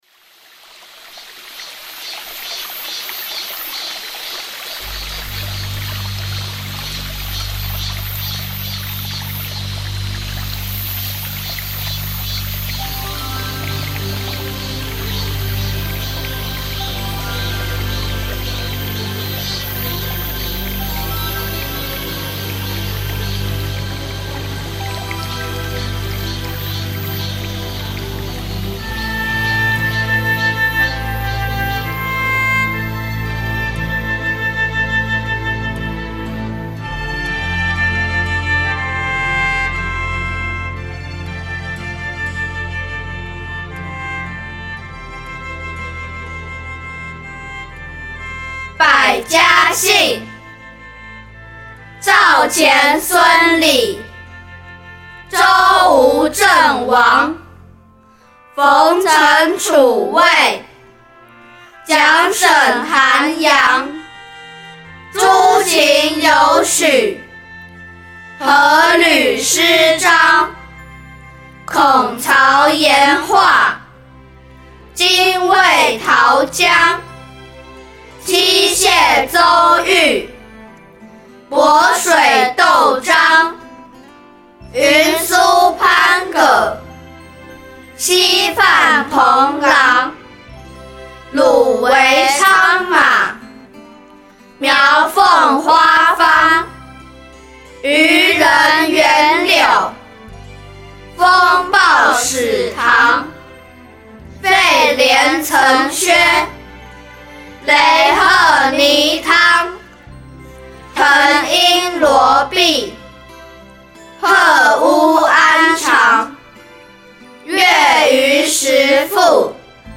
新韵传音《百家姓》六经朗读_在线聆听_百家姓读诵MP3下载_素超人